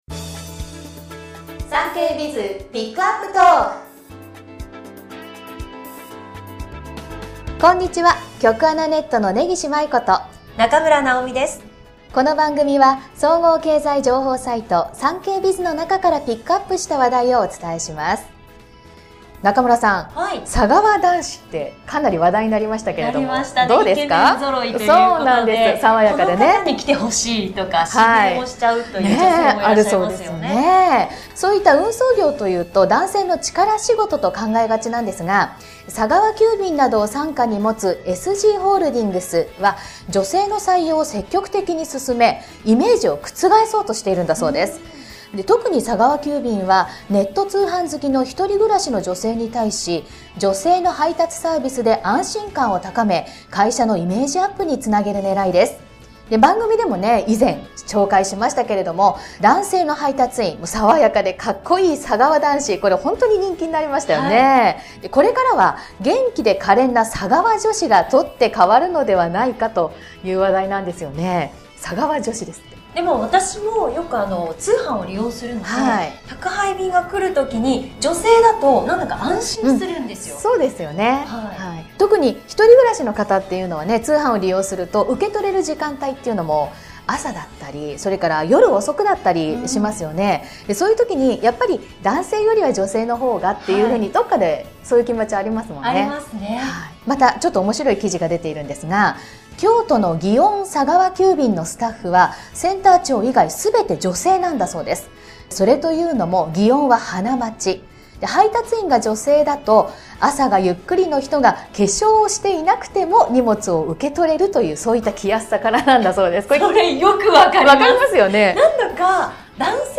全国240名の登録がある局アナ経験者がお届けする番組「JKNTV」